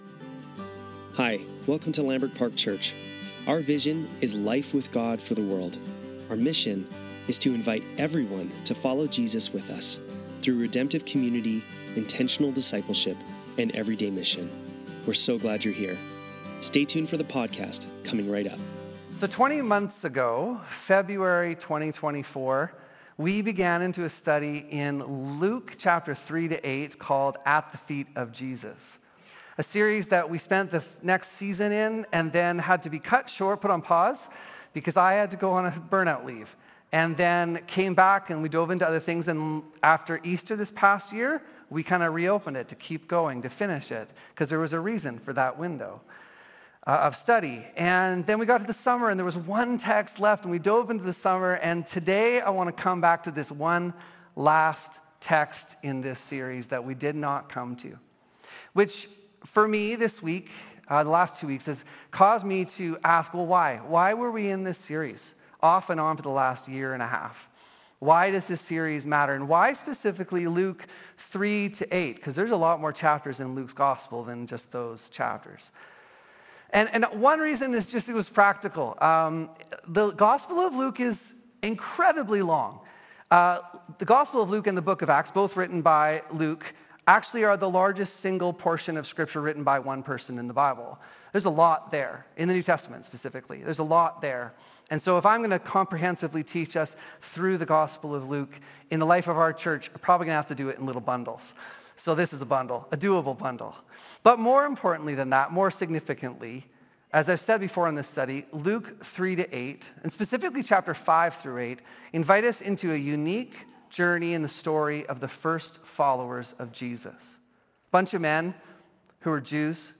Sunday Service - September 14, 2025